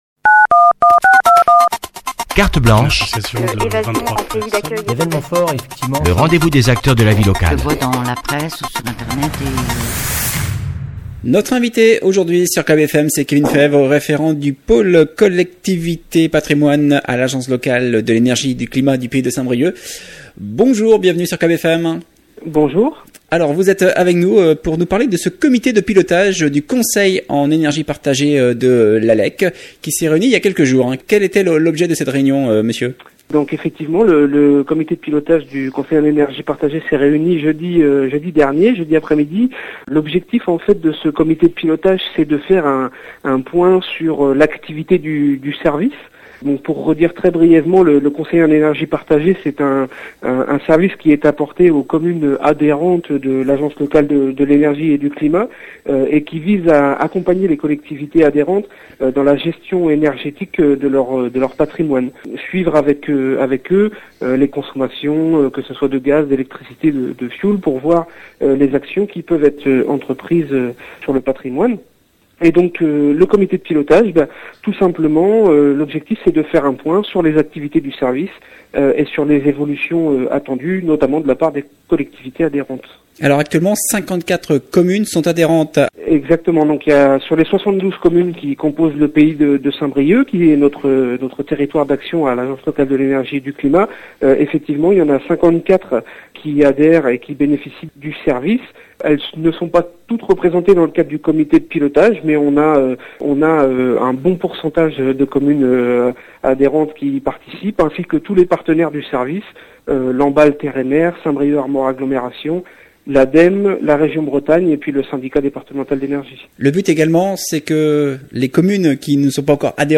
Cette entrée a été publiée dans Interviews.